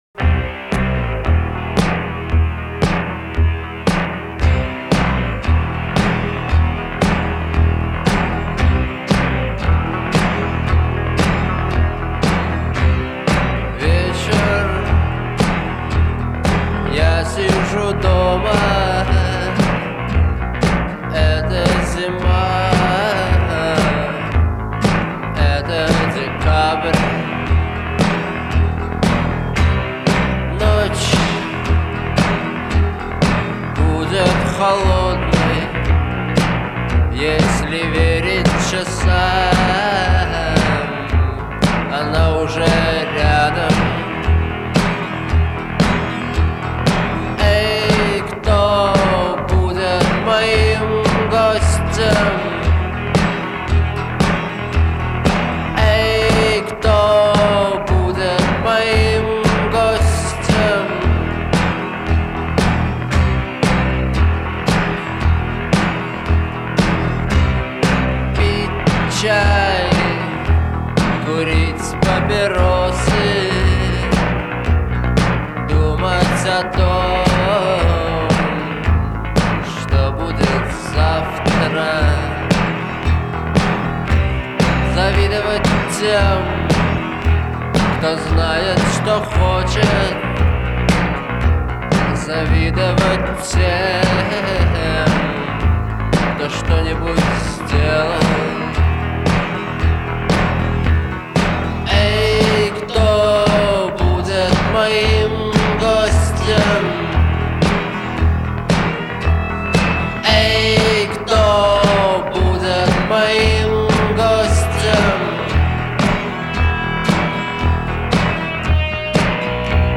характерной гитарной мелодией
проникновенным вокалом